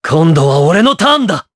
Esker-Vox_Skill4_jp_b.wav